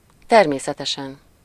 Ääntäminen
IPA: /ˈtɛrmeːsɛtɛʃɛn/